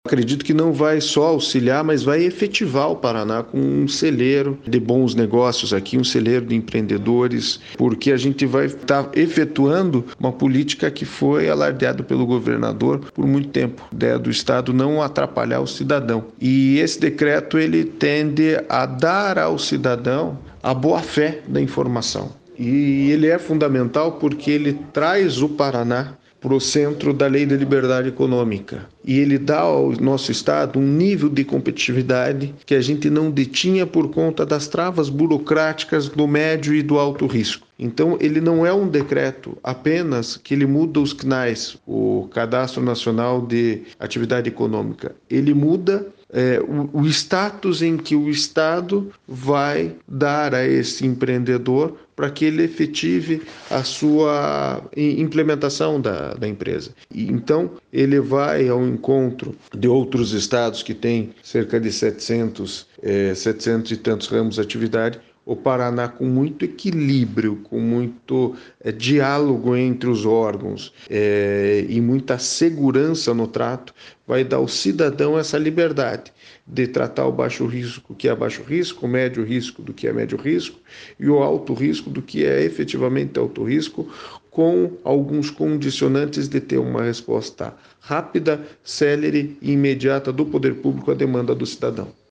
Sonora do secretário interino da Indústria, Comércio e Serviços, Christiano Puppi, sobre o decreto que dispensa licenças para mais de 770 atividades